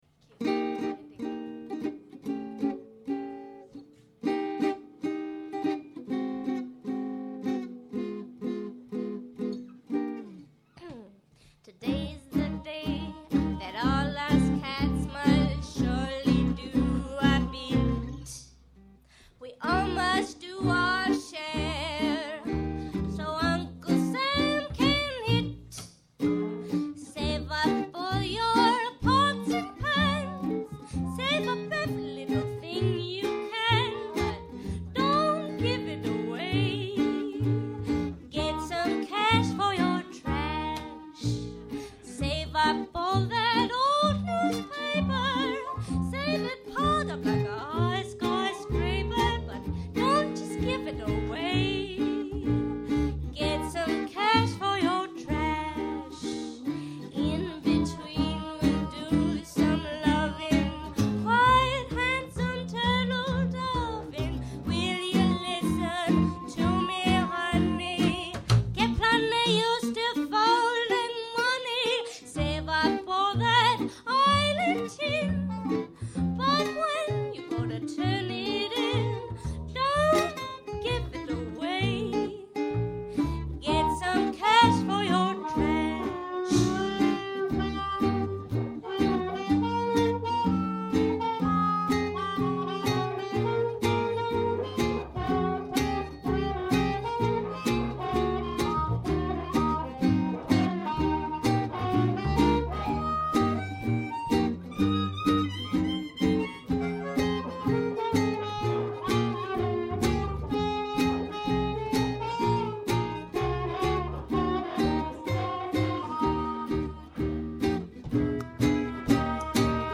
Check out some of their tracks, recorded live @ Banjo Jim's: